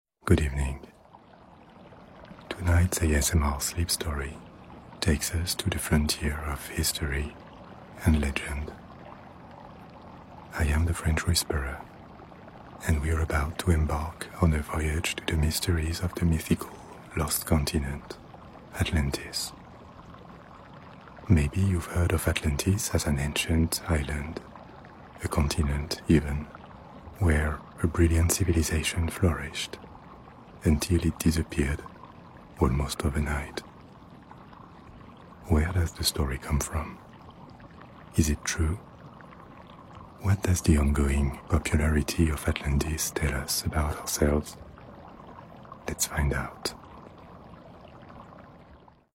987 This ASMR Sleep Story sound effects free download